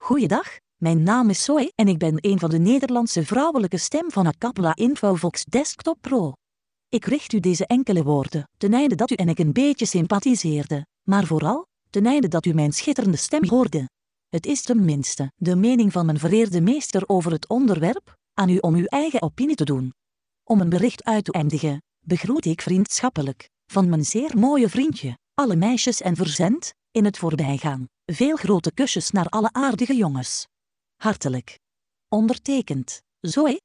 Texte de démonstration lu par Zoe, voix féminine néerlandaise d'Acapela Infovox Desktop Pro
Écouter la démonstration de Zoe, voix féminine néerlandaise d'Acapela Infovox Desktop Pro